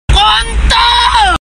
Kategori: Suara viral